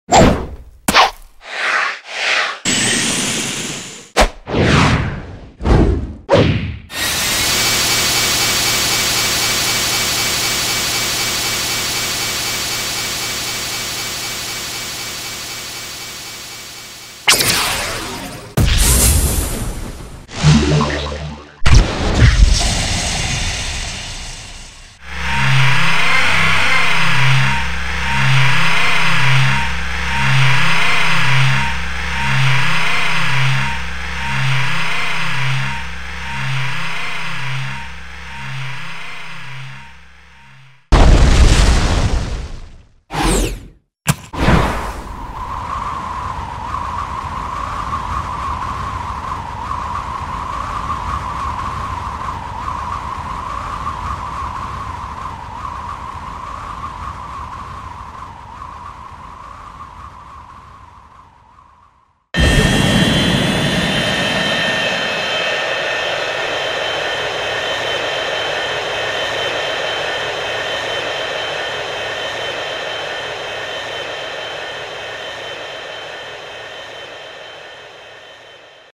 2. Подборка эффектов Sonic shadow